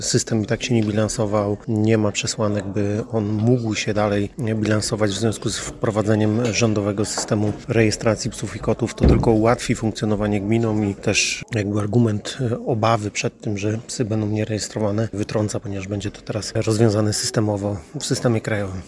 Burmistrz Piotr Wolny tłumaczy, że chodziło przede wszystkim o kwestie finansowe.